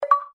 notify.wav